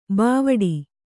♪ bāvaḍi